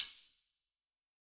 HTAMBOURIN13.wav